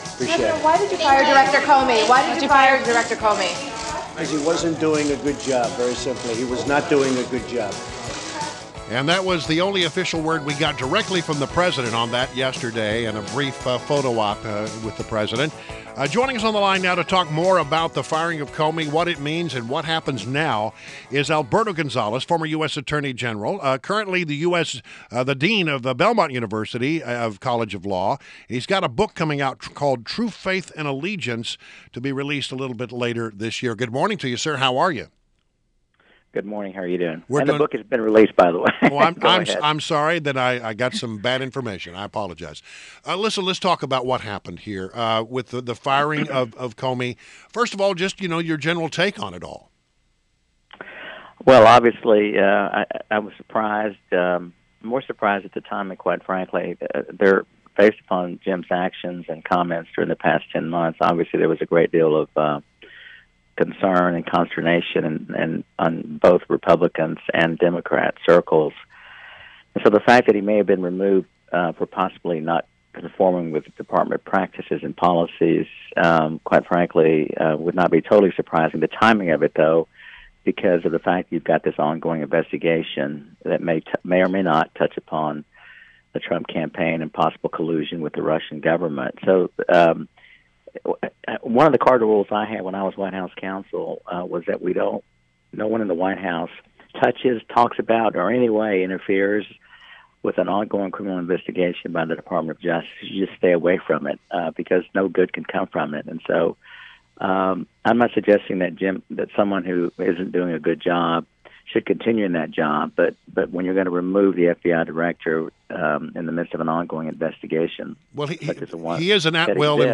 WMAL Interview - ALBERTO GONZALES - 05.11.17
INTERVIEW – ALBERTO GONZALES – former U.S. Attorney General, currently the DEAN of BELMONT UNIVERSITY COLLEGE OF LAW and author of TRUE FAITH AND ALLEGIANCE.